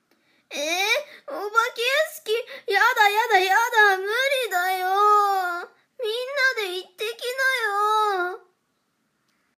サンプルボイス 気弱 【少年】